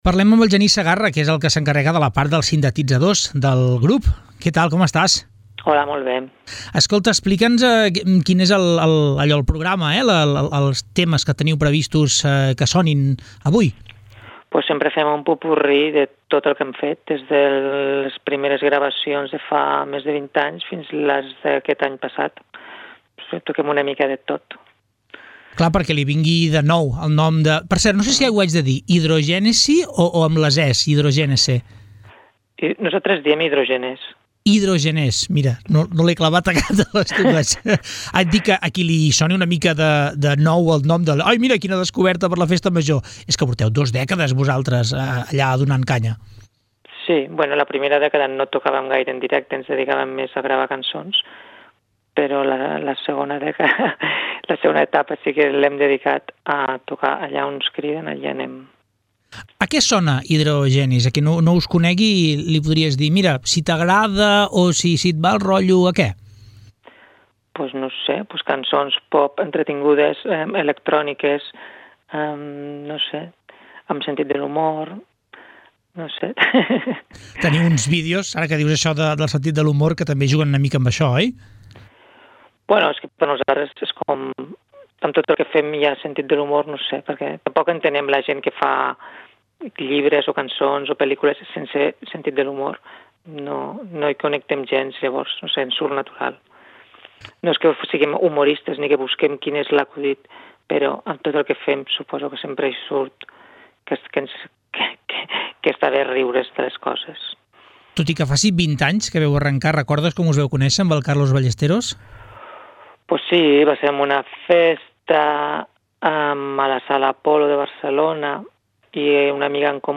Entrevista Hidrogenesse.mp3